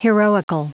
Prononciation du mot heroical en anglais (fichier audio)
Prononciation du mot : heroical